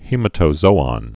(hēmə-tō-zōŏn, hĭ-mătə-)